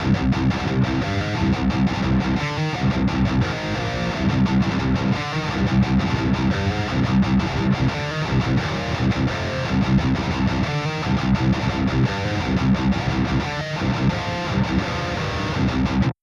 Also Kemper Profil mit einem Amp in eine Loadbox erstellt, einmal als Direct Profile und einmal durch einen Impulse Loader durch.
Danach dann die selbe IR auf das IR Profile gepackt. Das war jetzt nur kurz den 5150 an so wie er gerade rumstand. 1.